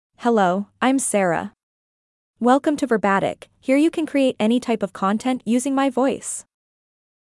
Sara — Female English (United States) AI Voice | TTS, Voice Cloning & Video | Verbatik AI
Sara is a female AI voice for English (United States).
Voice sample
Female
Sara delivers clear pronunciation with authentic United States English intonation, making your content sound professionally produced.